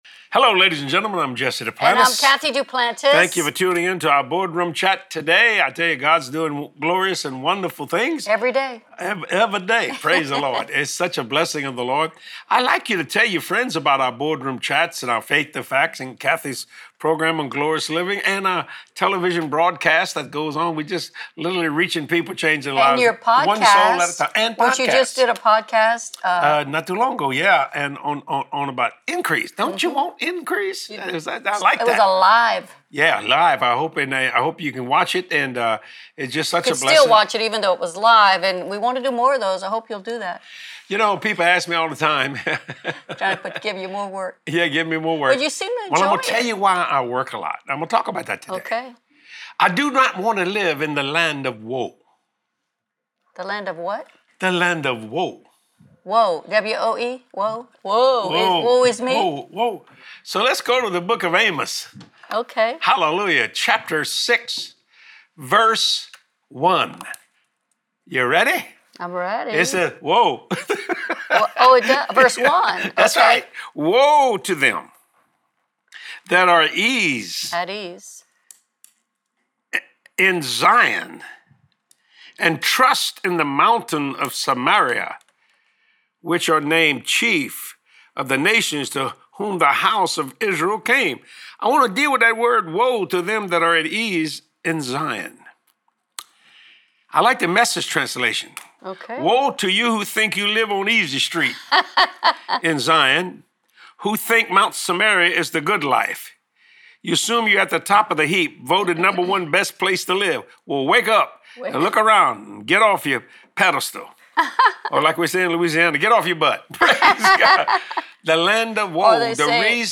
You should always be at peace, but never at ease. Watch this anointed teaching